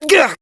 hotshot_hurt_08.wav